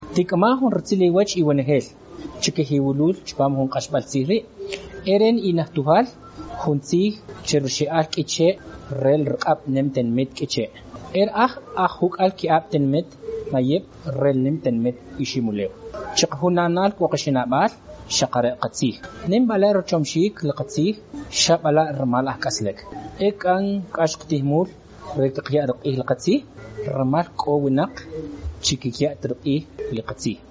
26 April 2020 at 10:40 pm A varied set of consonants. Clicks, trilled rhotics, what sounds like [t̠ʃ’], and so forth.
What I thought were clicks sound more like glottalized stops.